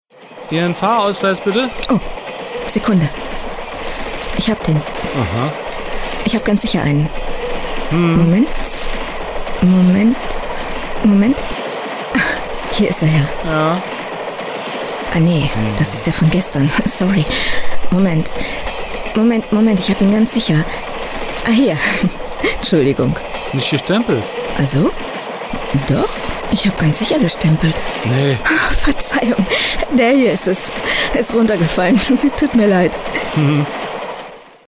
Markante warme, weiche Stimme mit Tiefe, die berührend und sinnlich sein kann.
Sprechprobe: Sonstiges (Muttersprache):